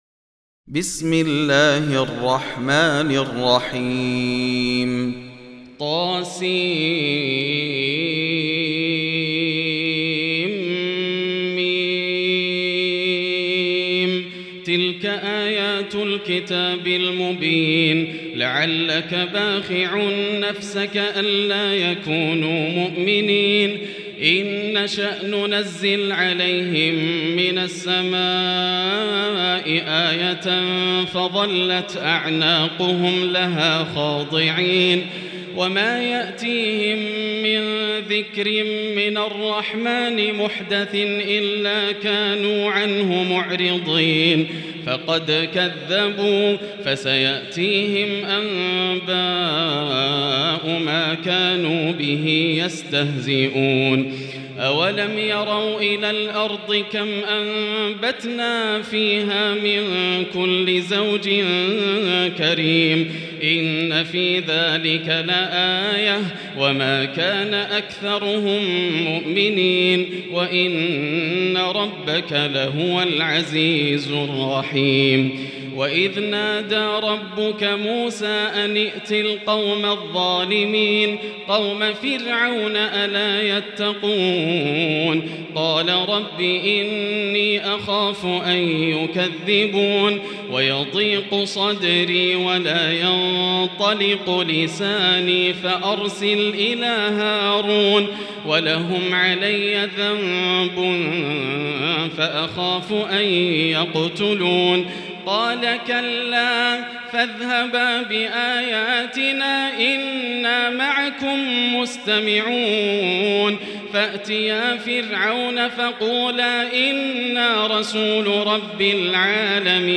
المكان: المسجد الحرام الشيخ: معالي الشيخ أ.د. بندر بليلة معالي الشيخ أ.د. بندر بليلة فضيلة الشيخ ياسر الدوسري الشعراء